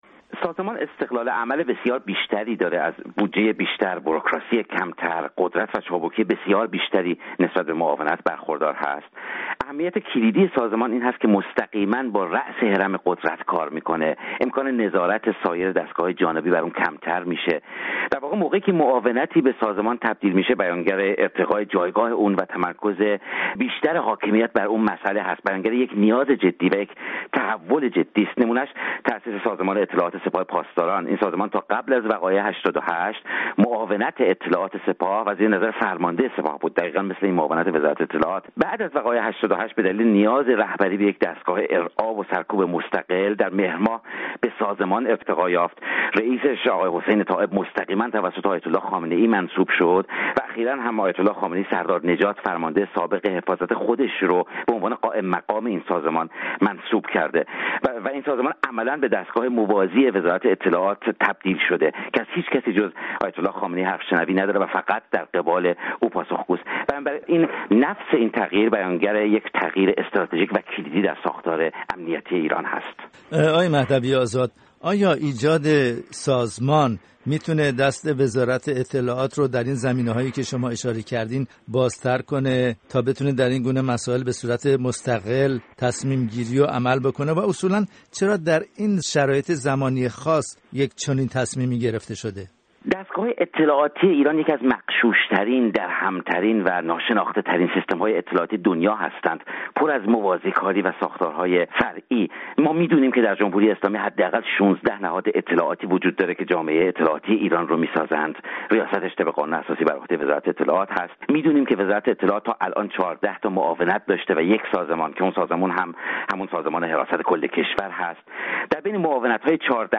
در گفت‌وگو با